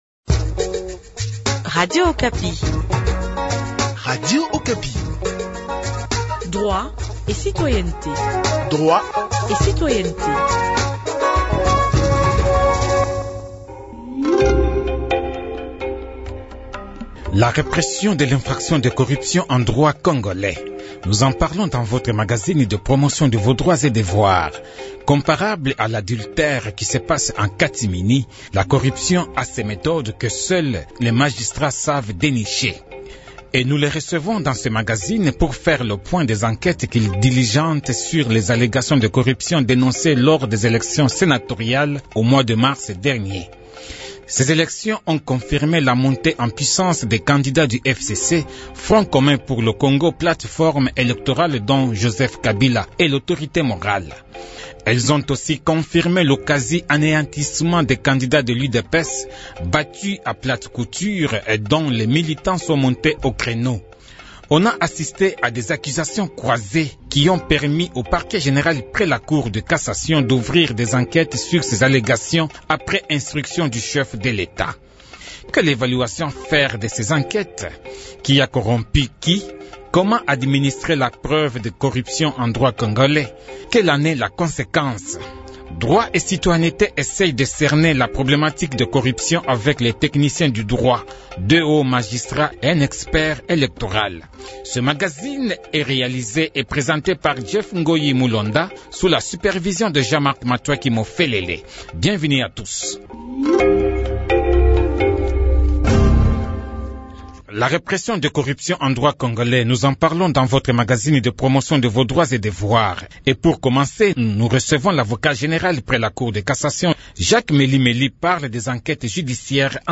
Et nous les recevons dans ce magazine pour faire le point des enquêtes qu’ils diligentent sur les allégations de corruption dénoncées lors des élections sénatoriales du 15 mars dernier.